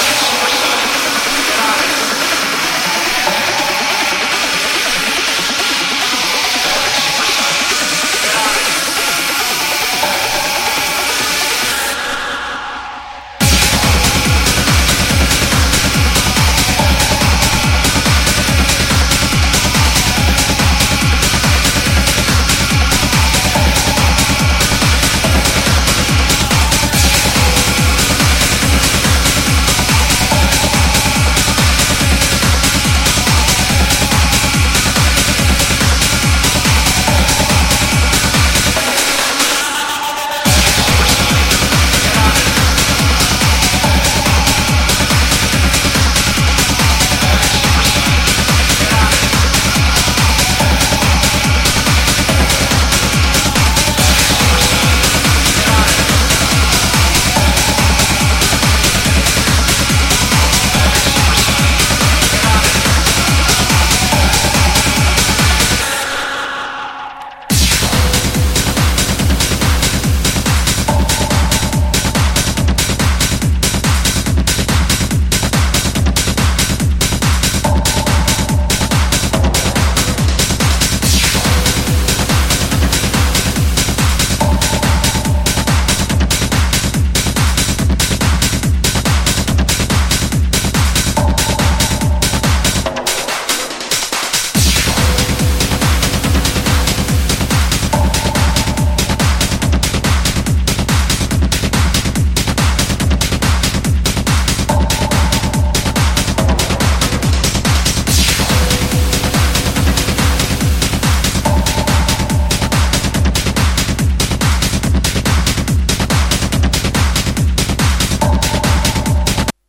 Acid Techno / Hard Techno Lp